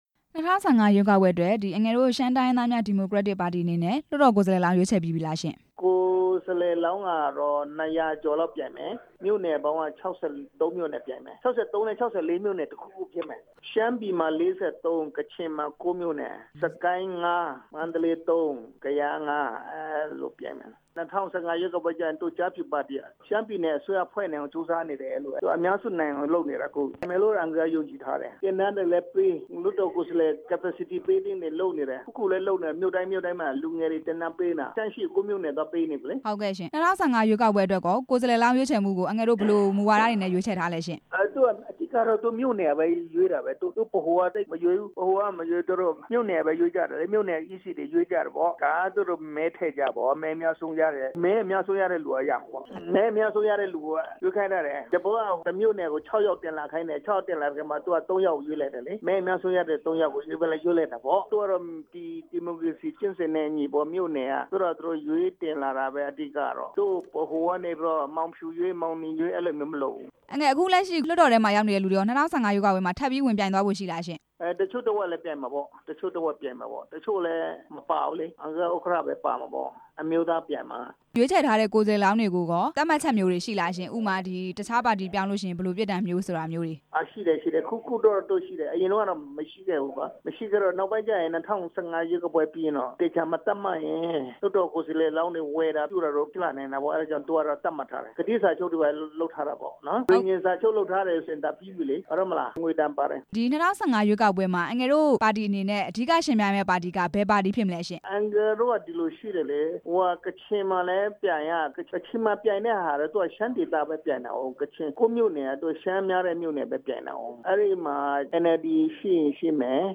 ဦးစိုင်းအိုက်ပေါင်းနဲ့ မေးမြန်းချက်